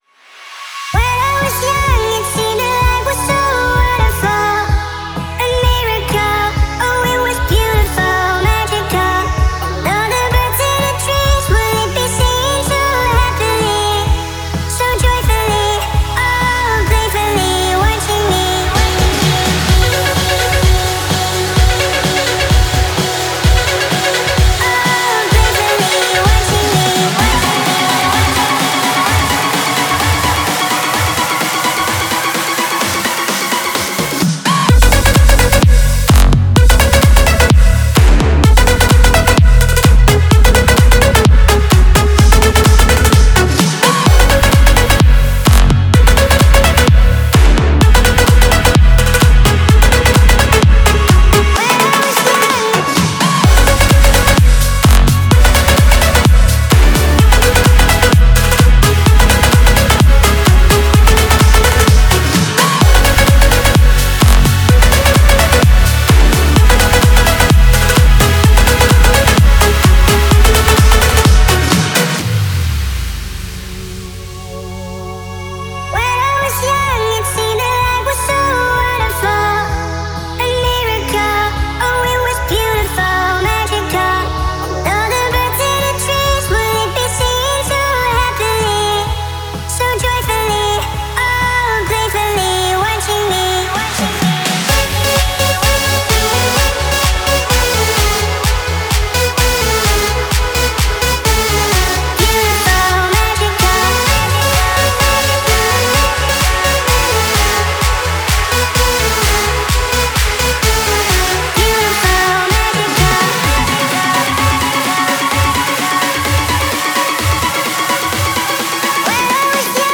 динамичные электронные ритмы создают запоминающееся звучание